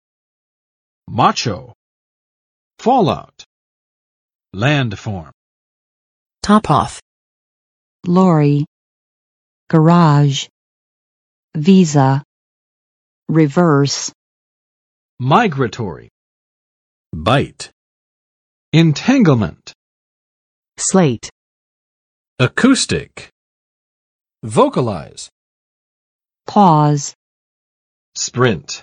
[ˋmɑtʃo] adj. 大男子气的